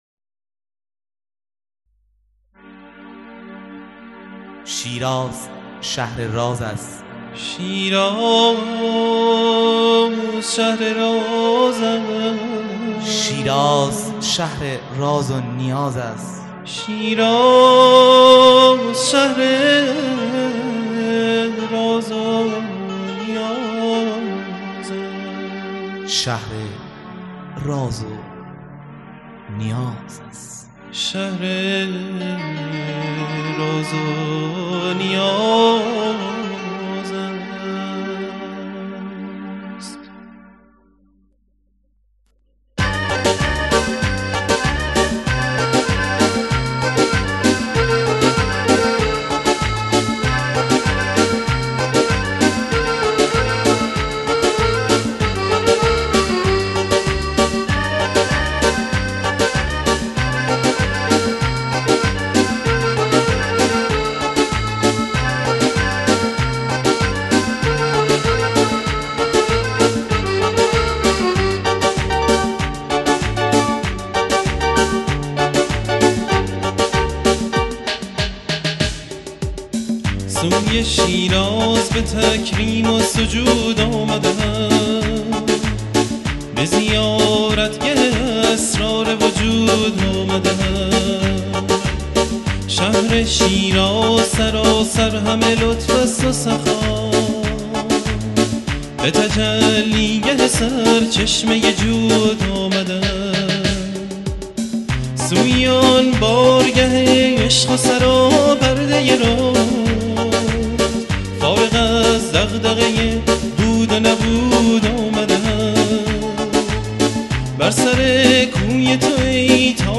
سرود - شماره 9 | تعالیم و عقاید آئین بهائی